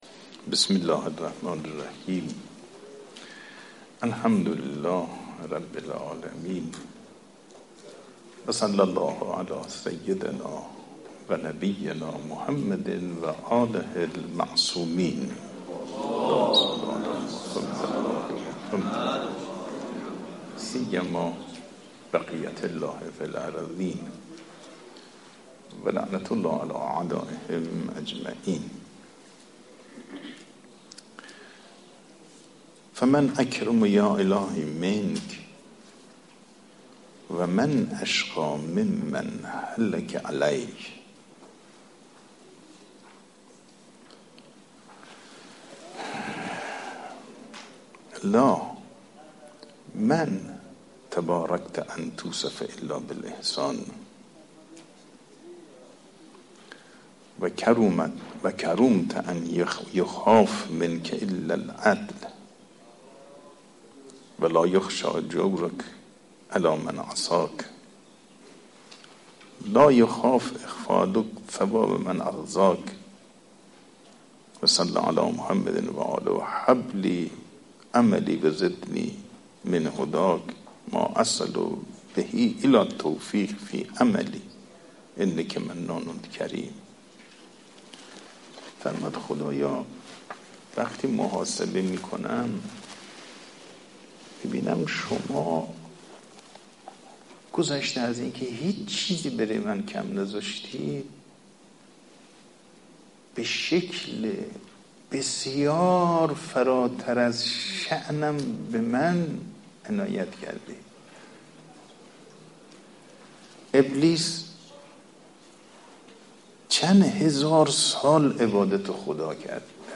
صوت | درس اخلاق امام جمعه بوشهر در مدرسه علمیه امام خمینی (ره)
حوزه/ درس اخلاق حجت‌الاسلام والمسلمین صفایی بوشهری در مدرسه علمیه امام خمینی (ره) بوشهر برگزار شد.